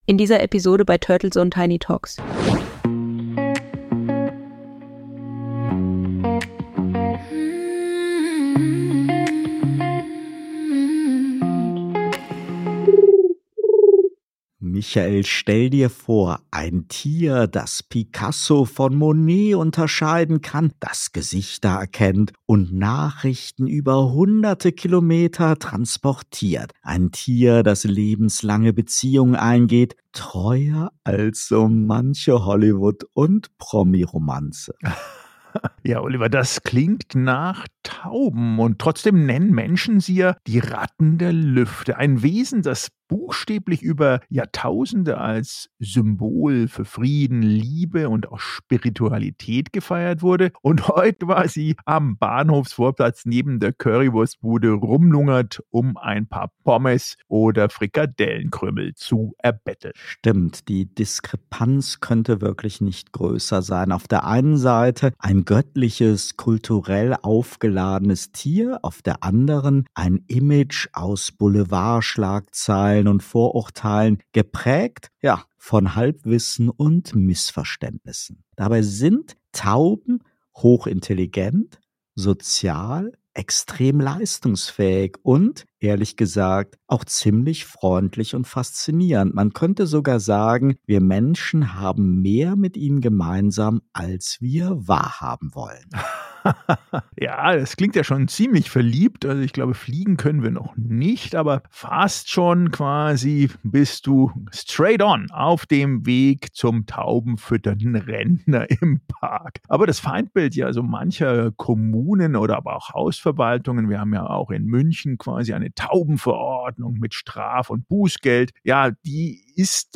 Als Bonus gibt es den Song "Feathers of the City".